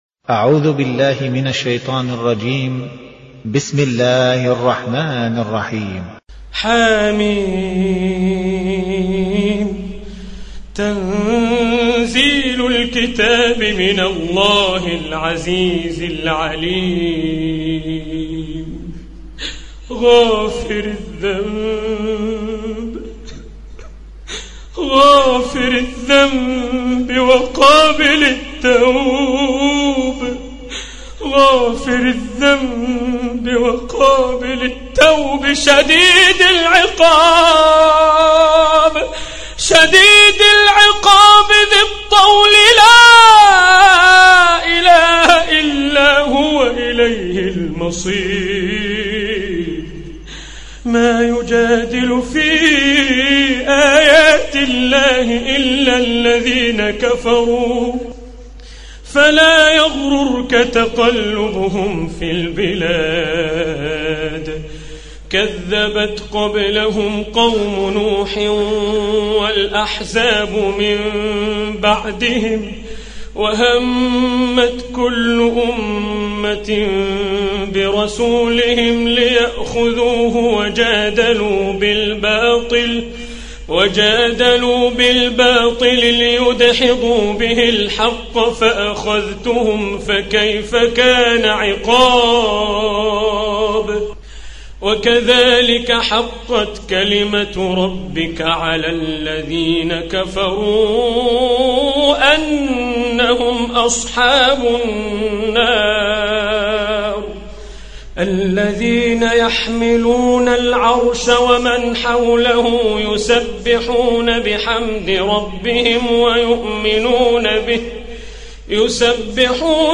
Surah Repeating تكرار السورة Download Surah حمّل السورة Reciting Murattalah Audio for 40.